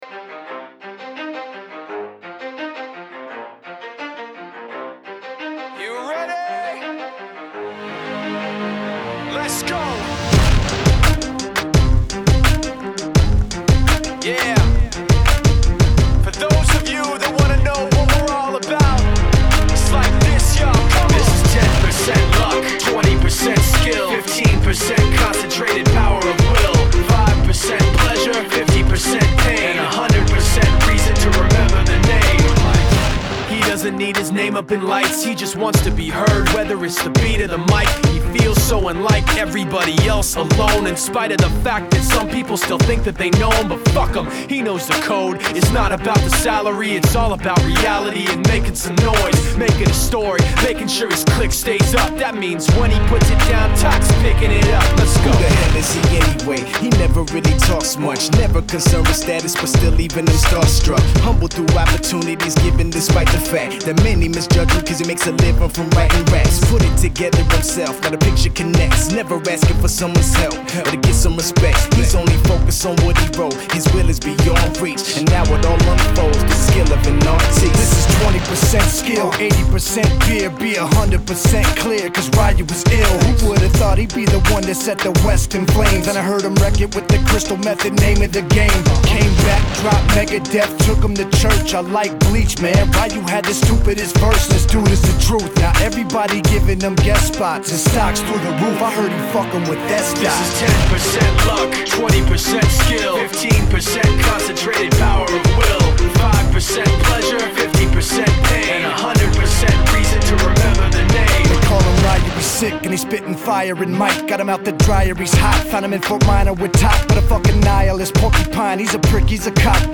HipHop 2000er